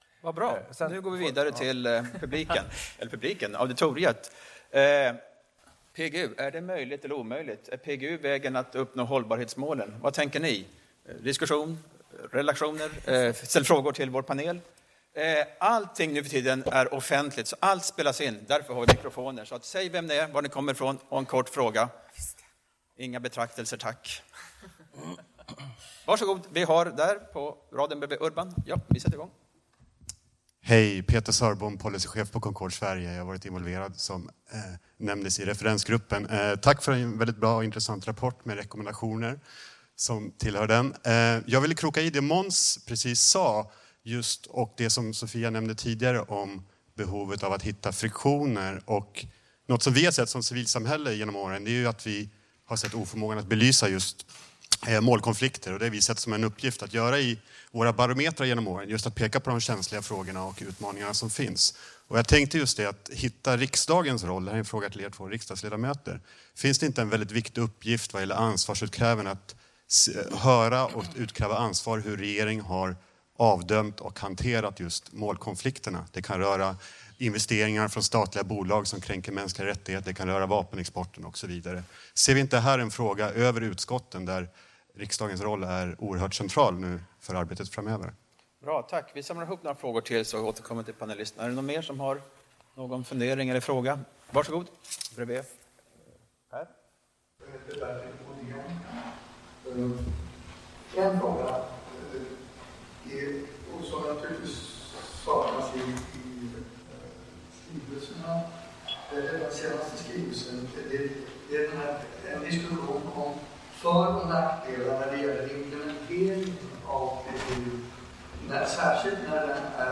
Part III Frågor från publiken